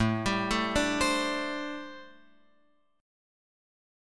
Am11 chord